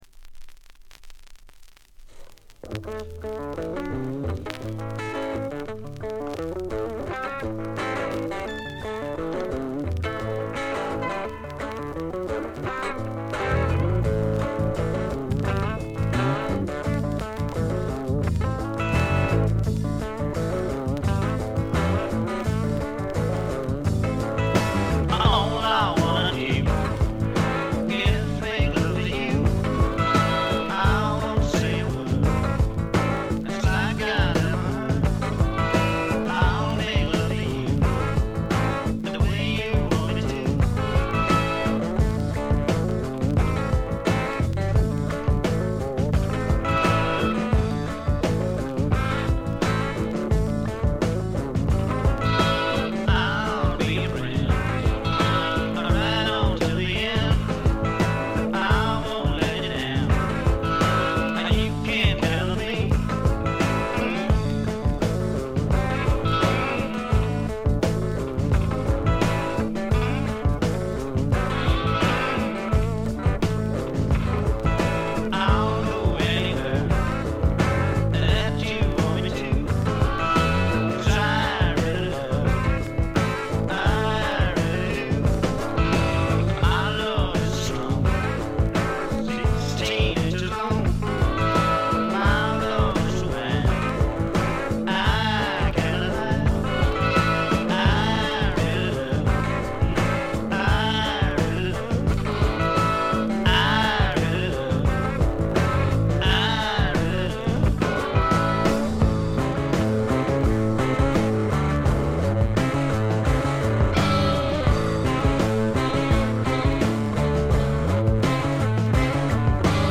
バックグラウンドノイズ、チリプチ、プツ音等多め大きめですが、鑑賞を妨げるほどではなく普通に聴けるレベルと思います。
内容は笑っちゃうぐらい売れなさそうな激渋スワンプ。
試聴曲は現品からの取り込み音源です。